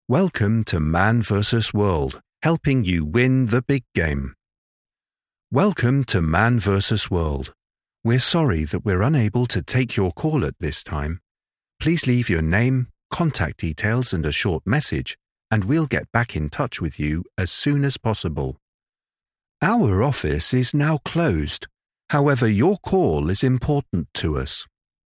IVR
I have a warm and engaging English RP accent which is suitable for a wide range of projects, including audiobook, corporate and commercial.
I produce audio from my purpose built home studio where I use a Shure SM7B mic with a Focusrite Scarlet 2i4 interface and Logic Pro on a Mac.
BaritoneBass